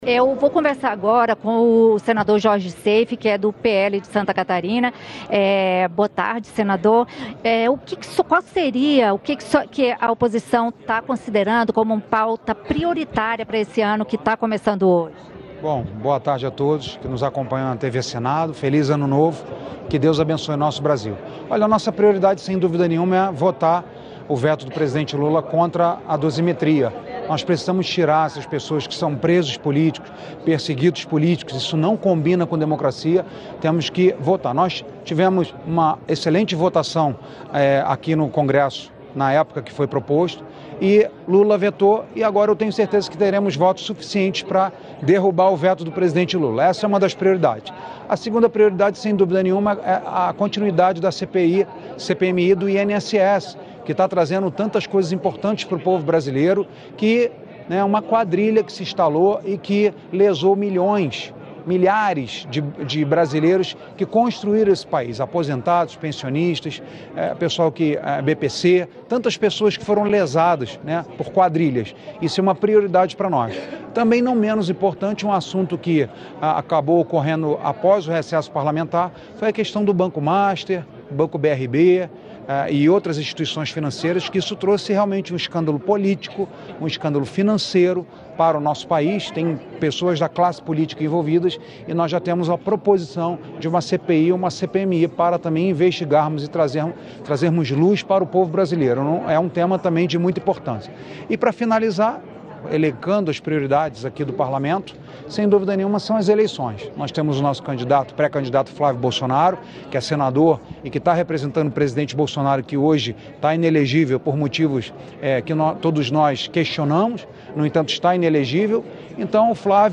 Em entrevista à Rádio Senado nesta segunda-feira (2), o senador Jorge Seif (PL-SC) destacou quais devem ser os itens da pauta prioritária da oposição neste ano. Entre eles estão a análise do veto do presidente Luiz Inácio Lula da Silva ao projeto da dosimetria; a continuidade das investigações da CPMI do INSS; e as investigações envolvendo o Banco Master.